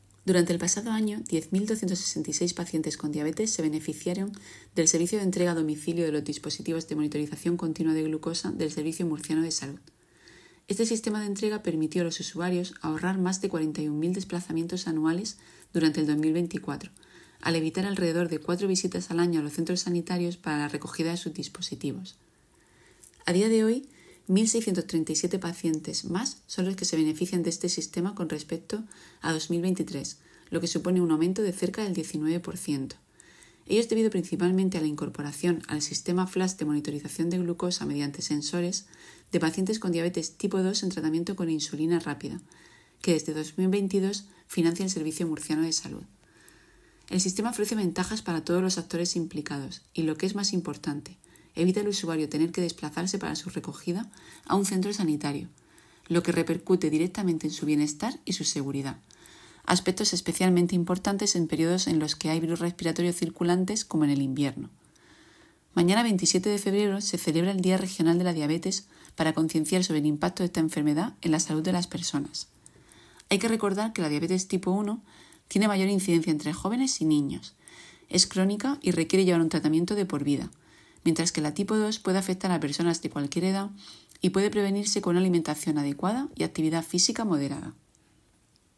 Declaraciones de la directora gerente del Servicio Murciano de Salud, Isabel Ayala, sobre los dispositivos de monitorización continua de glucosa para pacientes con diabetes